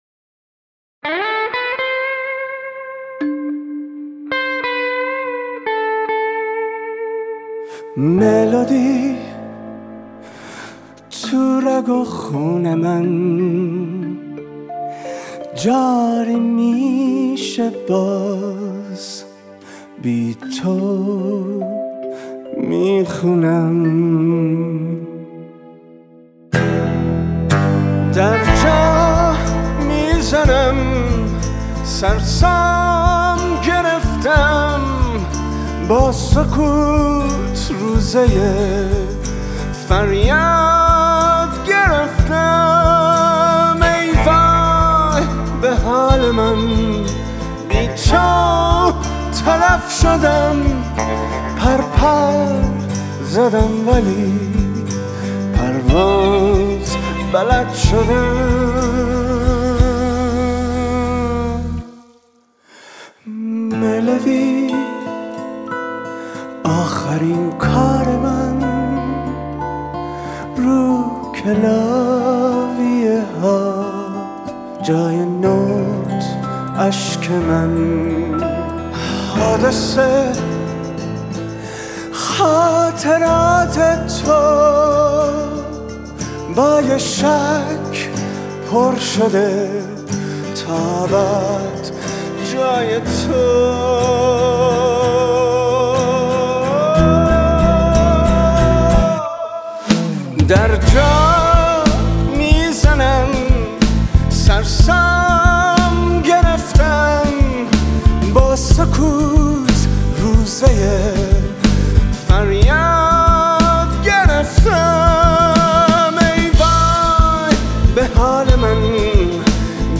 با ریتم 6/8 سنگین